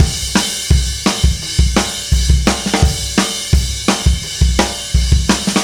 Indie Pop Beat 05 Crash Repeat.wav